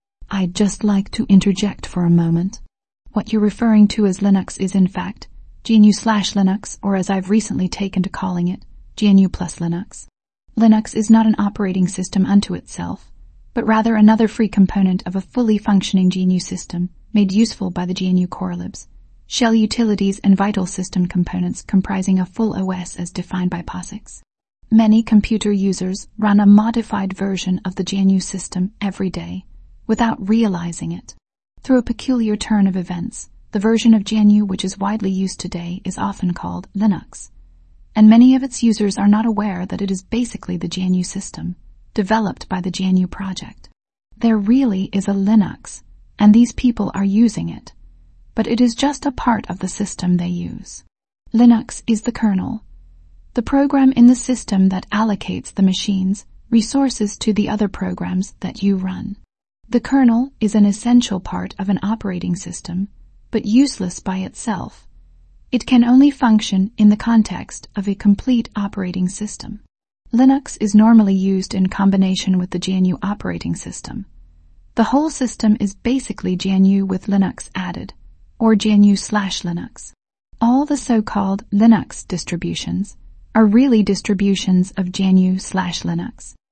here's StyleTTS2, using a small sample of the NPR type voice i used from elevenlabs as a reference.